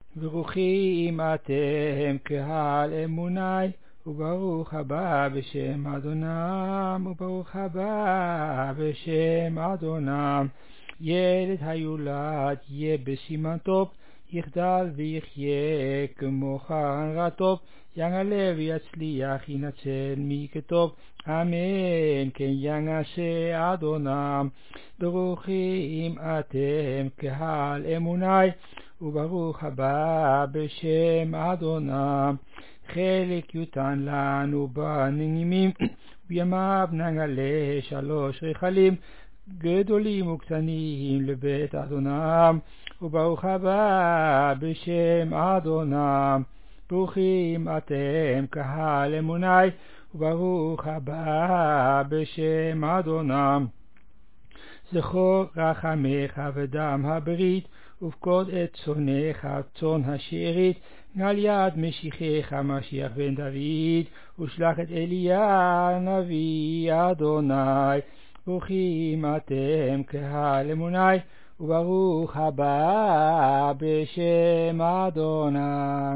When the baby is brought in, all sing (the last phrase is sung later, see below)
reconstructed melody
As the recoding was made during a berit mila, the quality is moderate.